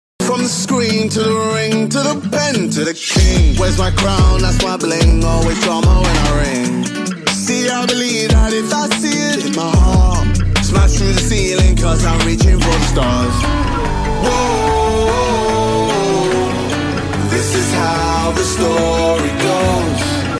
“Lays” Logo But Its With Sound Effects Free Download